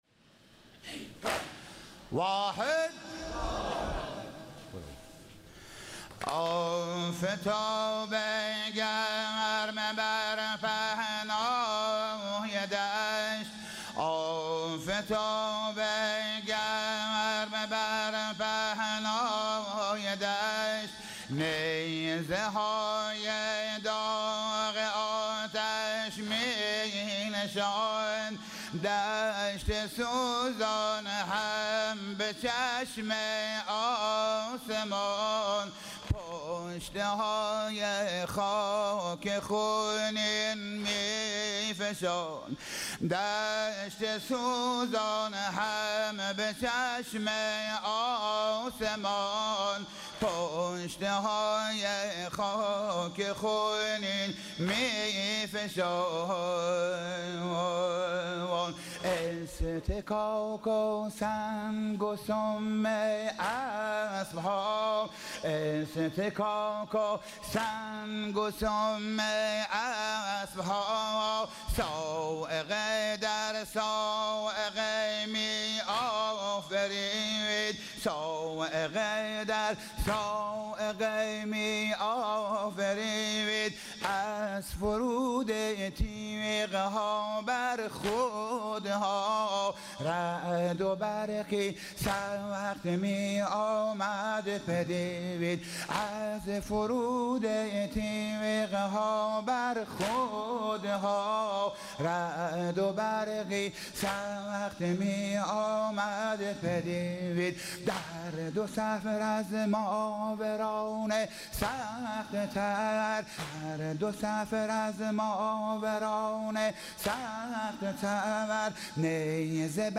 نوحه خوانی در رثای سالار شهیدان